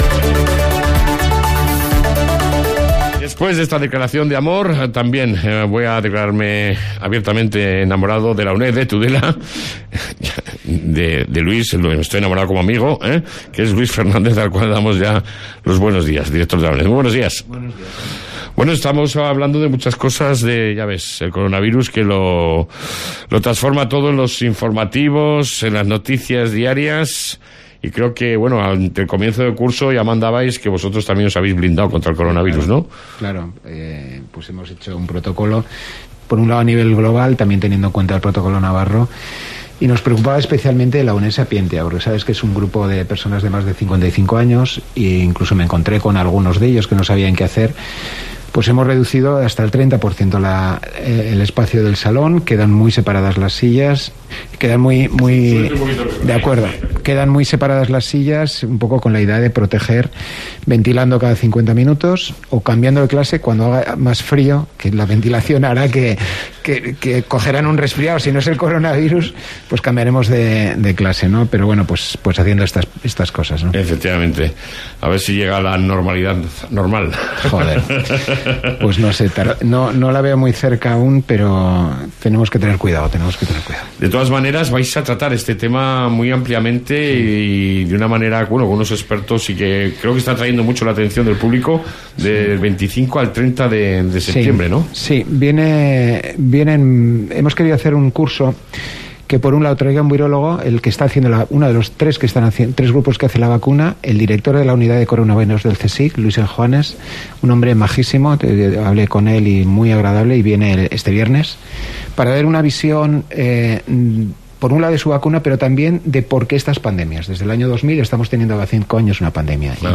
AUDIO: Entrevista con la UNED de Tudela